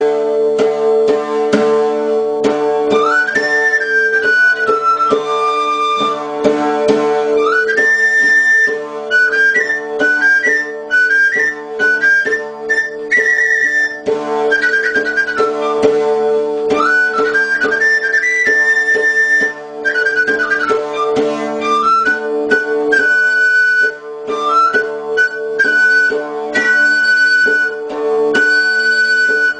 CHICOTÉN Instrumento de cuerda que se percute con una baqueta. Se usa en la música tradicional del País Vasco y del norte de Aragón, principalmente para acompañar instrumentos de viento.